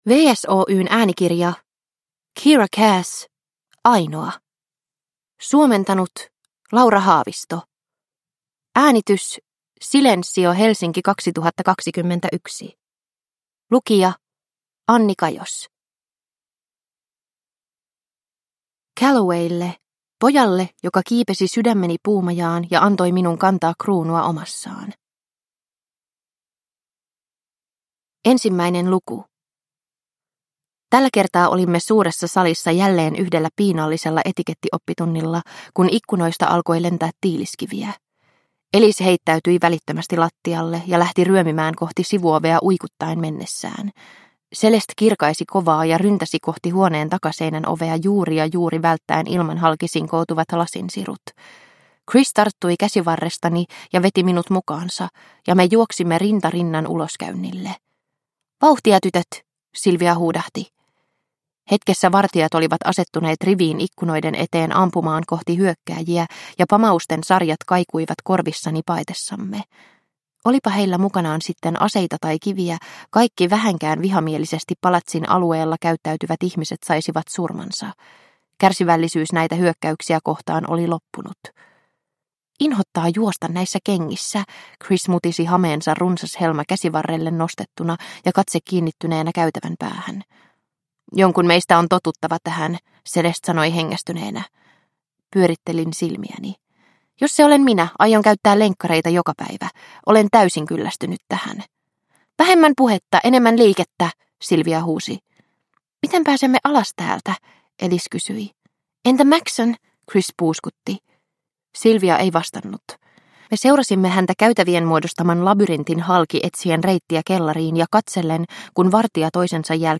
Ainoa – Ljudbok – Laddas ner